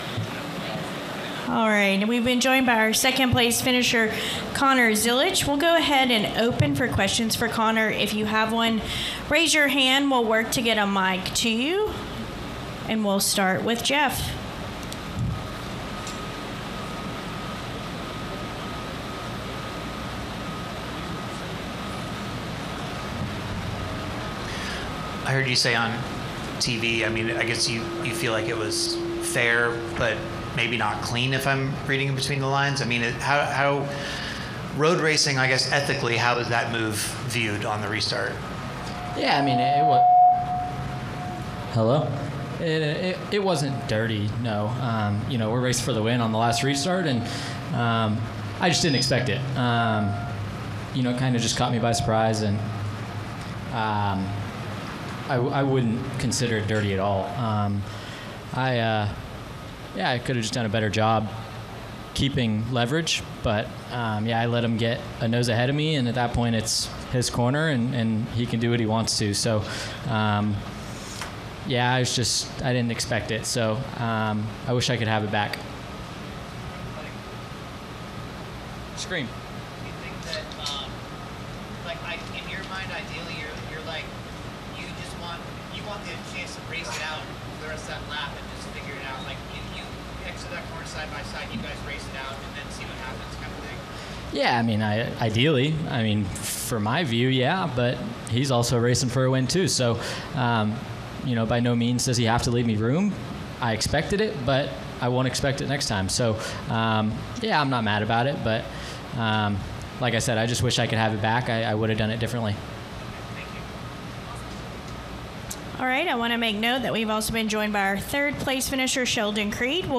Interviews:
NASCAR Xfinity Series second place Connor Zilisch (No. 88 JR Motorsports Chevrolet) and third place Sheldon Creed (No. 00 Haas Factory Team Ford) –